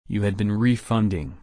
/ɹɪˈfʌnd/